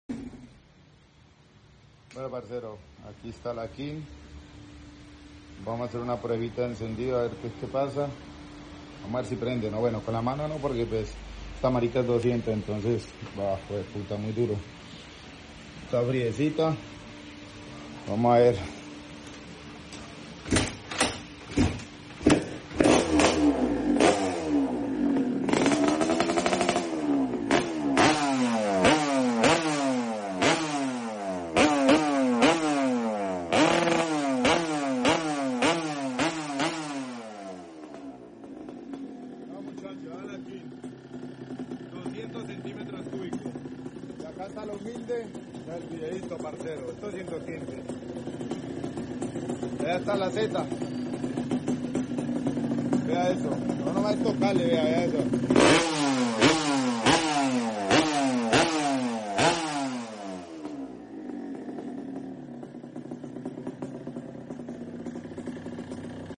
RX 200 Cc De Turbo Motos Sound Effects Free Download